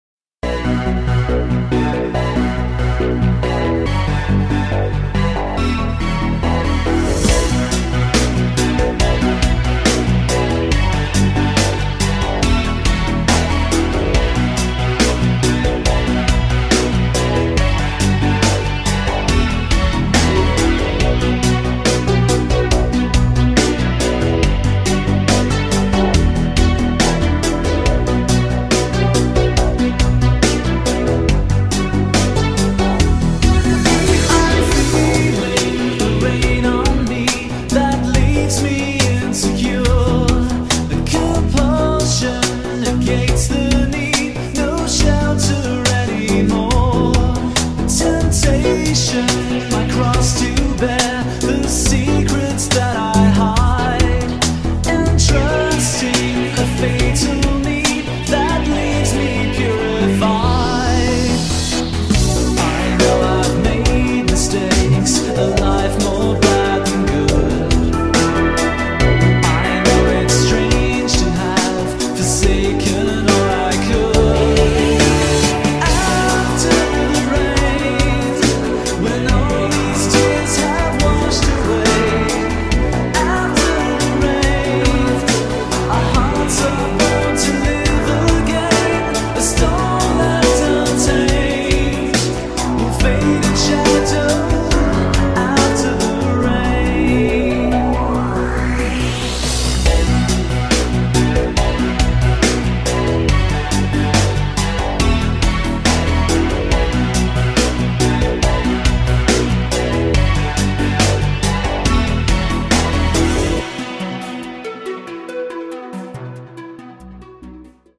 BPM140
Audio QualityCut From Video